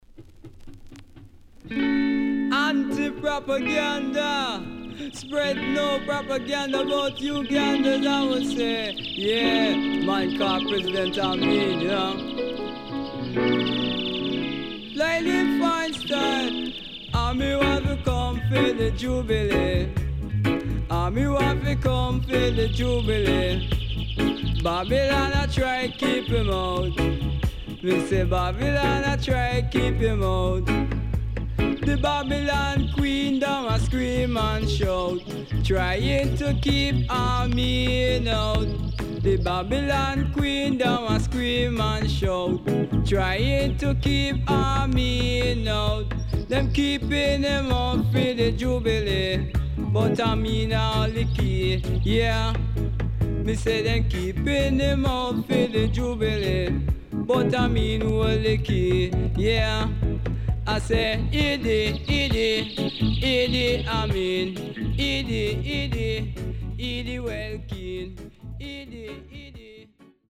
CONDITION SIDE A:VG+〜EX-
SIDE A:少しチリノイズ入ります。